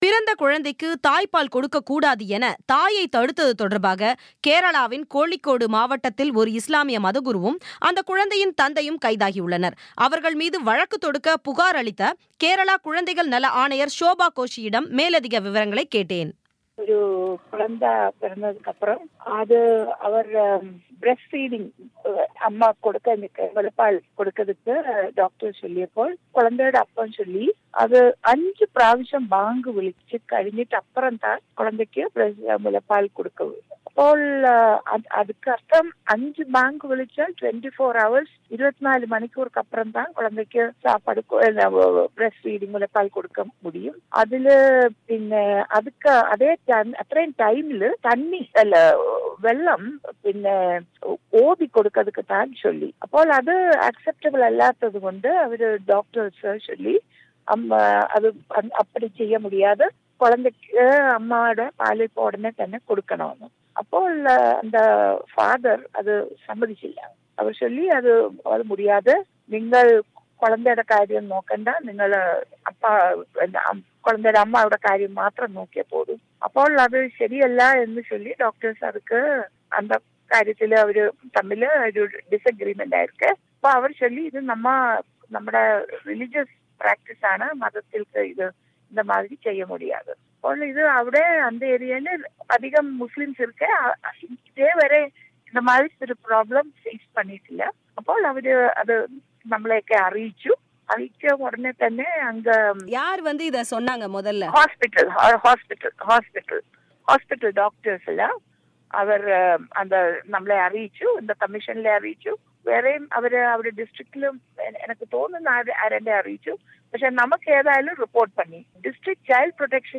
கேரளாவில் பிறந்த குழந்தைக்கு தாய் பாலூட்டுவதை தடுத்த தந்தை கைது குறித்த பேட்டி